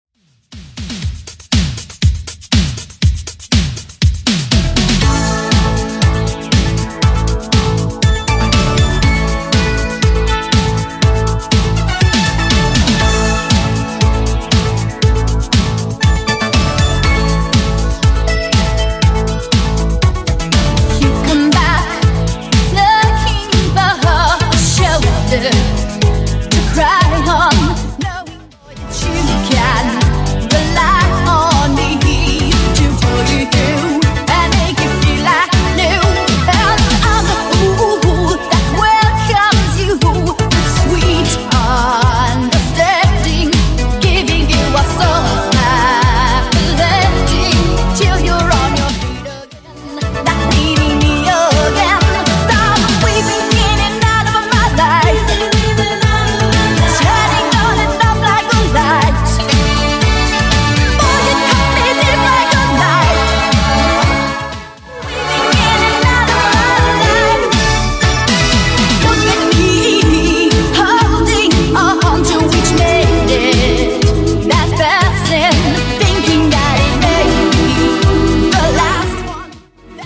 Genre: 80's
BPM: 178